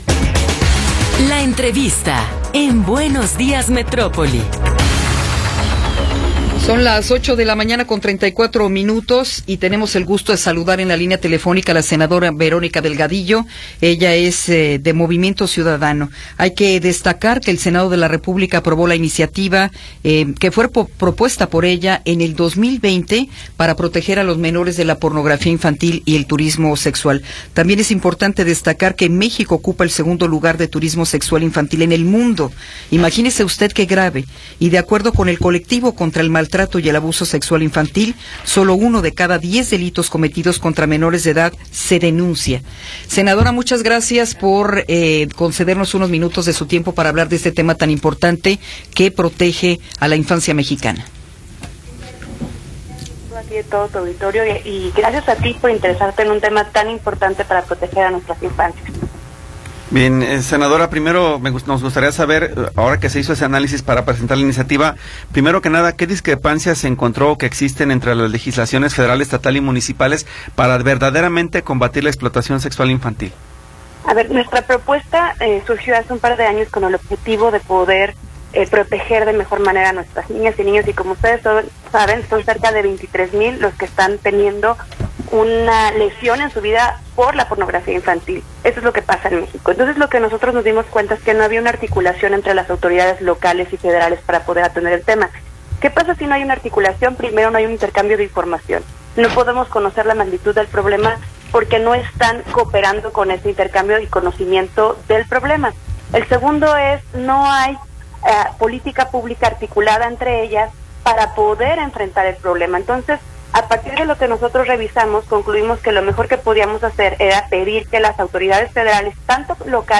Entrevista con Verónica Delgadillo
Verónica Delgadillo, senadora de Movimiento Ciudadano, nos habla sobre su iniciativa para proteger a menores de la pornografía y el turismo sexual.